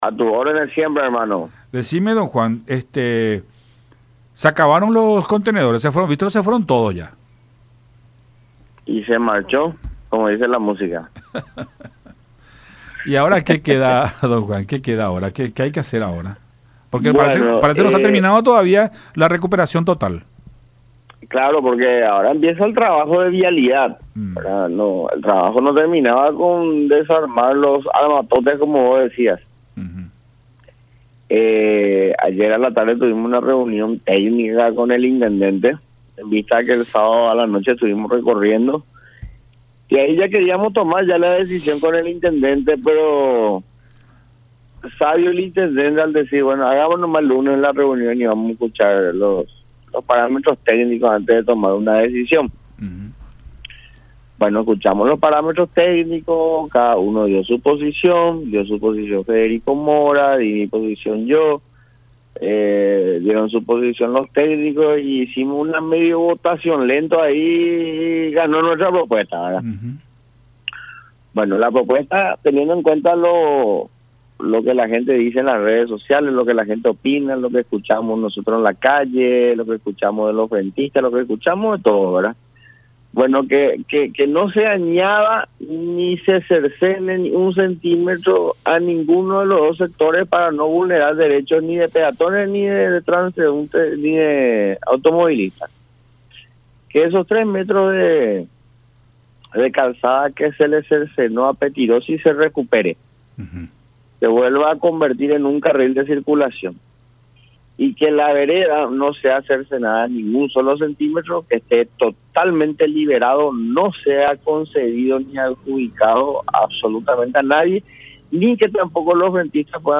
en charla con Nuestra Mañana a través de Unión TV y radio La Unión